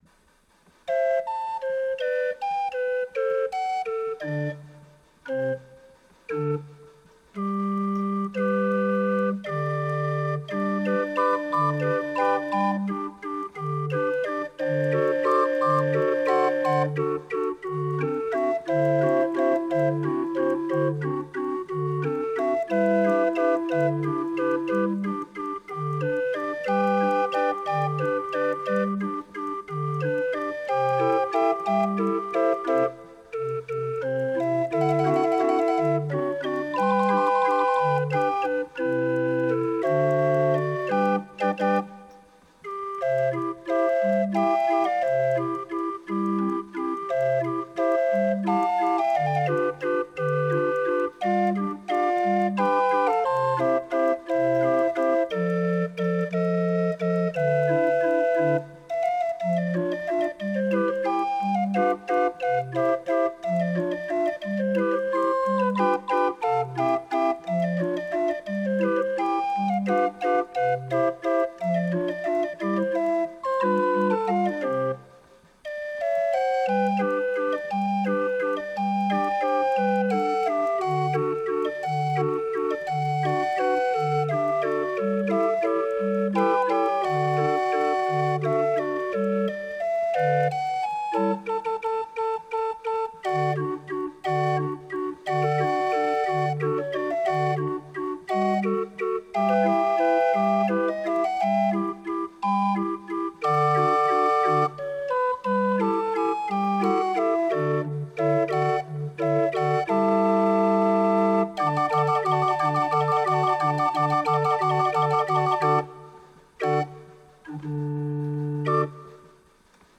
Vivez une expérience musicale unique et rassembleuse en compagnie de notre sympathique tourneur de manivelle et de son authentique orgue de barbarie fabriqué en Europe.